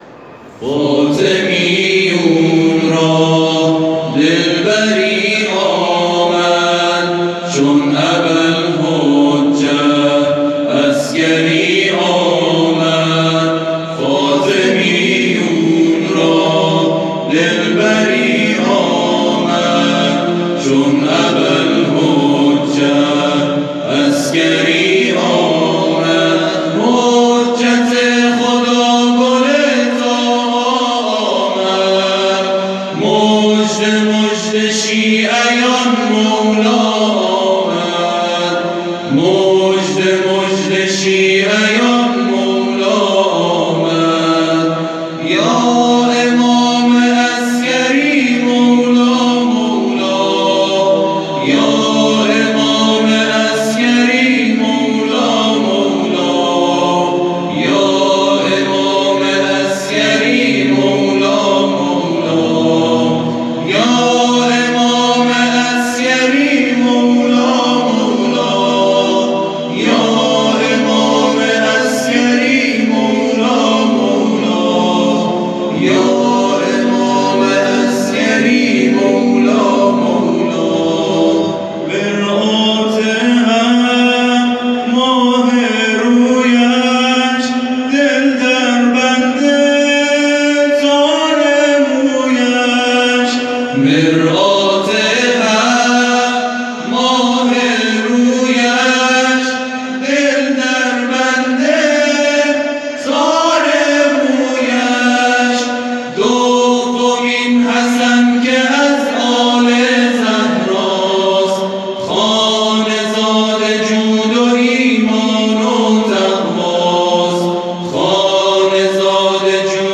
گروه شبکه اجتماعی: به مناسبت میلاد امام حسن عسکری(ع)، گروه تواشیح رضوان قم، تواشیحی در مدح امام یازدهم شیعیان اجرا کردند.
به گزارش خبرگزاری بین‌المللی قرآن(ایکنا) گروه تواشیح رضوان قم، روز گذشته، 18 دی‌ماه، به مناسبت میلاد امام حسن عسکری(ع) در حرم حضرت معصومه(س) تواشیحی در مدح امام یازدهم شیعیان اجرا کرد.
فایل صوتی این تواشیح در کانال تلگرامی این گروه منتشر شده است، یادآور می‌شود که گروه تواشیح و همخوانی رضوان قم، متشکل از جمعی از جوانان نخبه قمی در شهریور ماه سال ۱۳۹۱ با هدف رضایت و رضوان الهی کار خود را با اجرای برنامه‌های دینی و آئینی در مراسم و محافل معنوی و فرهنگی ـ مذهبی آغاز کرد.